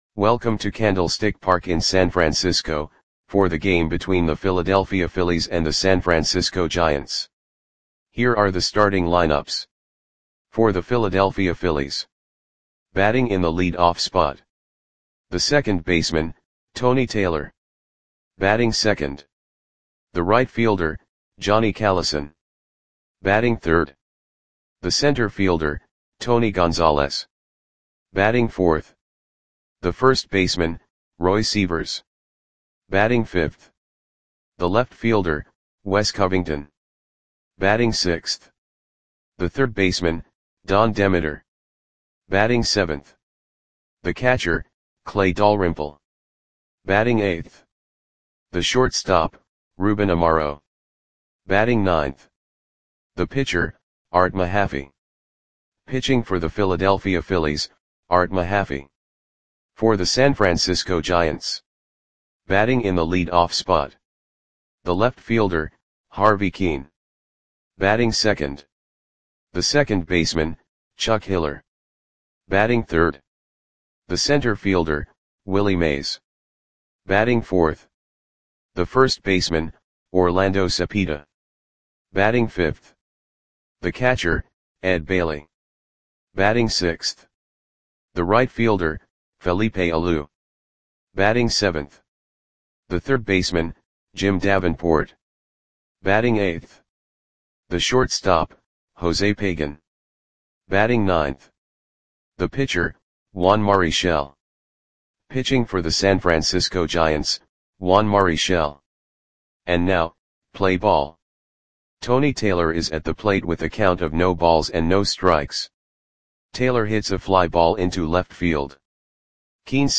Audio Play-by-Play for San Francisco Giants on August 7, 1962
Click the button below to listen to the audio play-by-play.